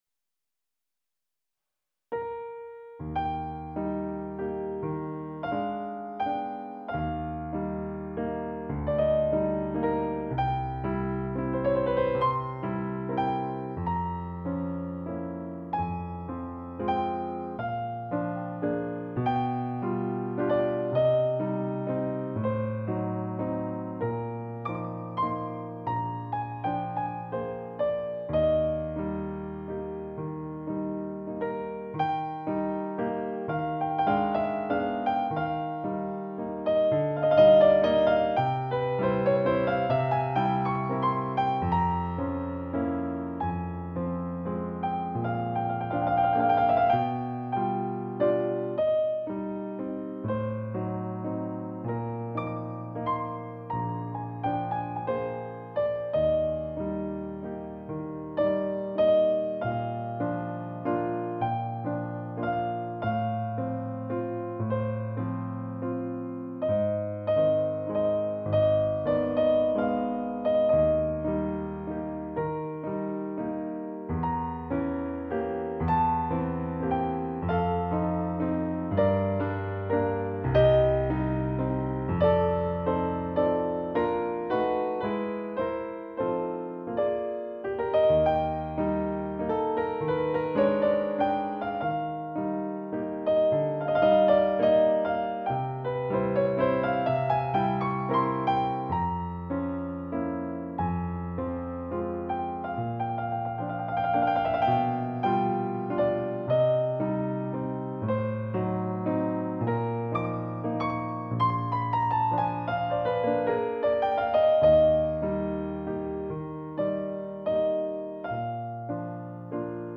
ピアノ（クラシック）、テニス